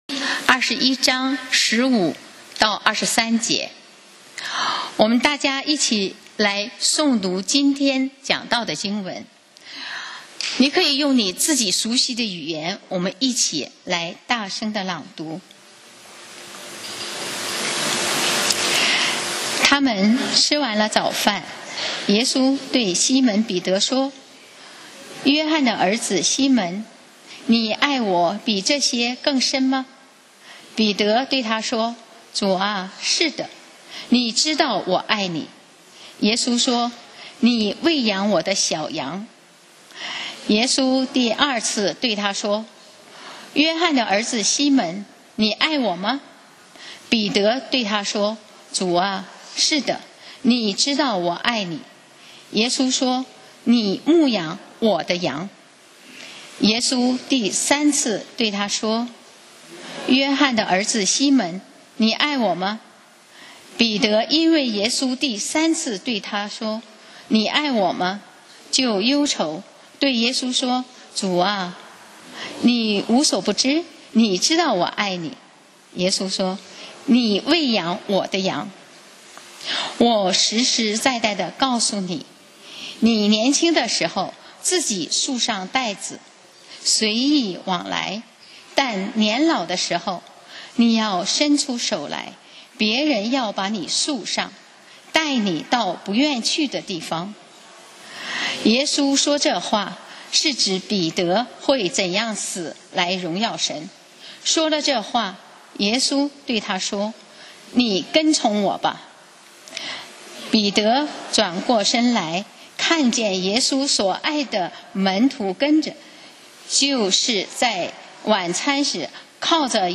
講道 Sermon 題目 Topic：你跟从我吧！